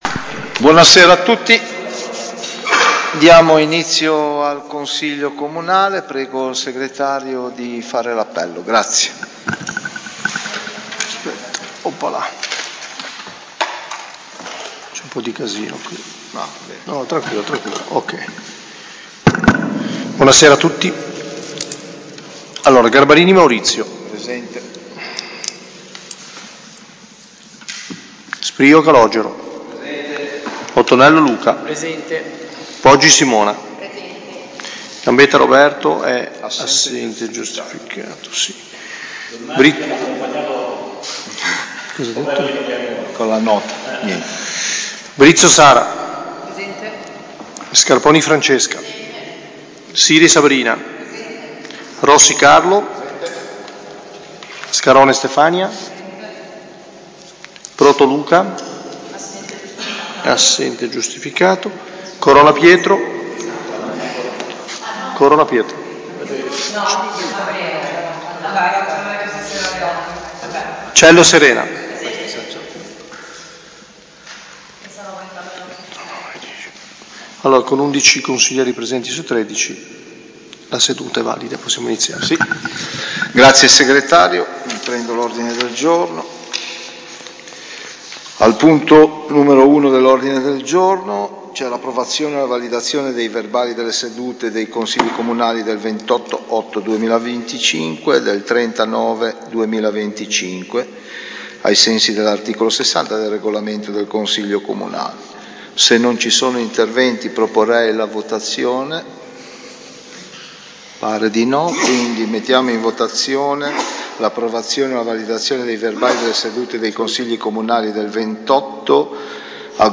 Seduta del Consiglio comunale mercoledì 29 ottobre 2025, alle 21.00, presso l'Auditorium comunale in via alla Massa.